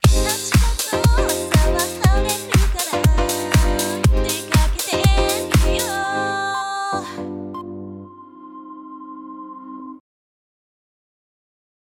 今回は、バックトラックのオケにボーカルシンセを使用した曲作りについて紹介しましょう。
以降、同様の手順を繰り返し進めていくことで、歌声メロディートラックを作成が完了します。
このような手順で作成したサンプルがこちらです。